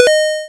Paradise/sound/machines/ping.ogg at 355666e1a825252a4d08fa4e5cfced85e107ce39
ping.ogg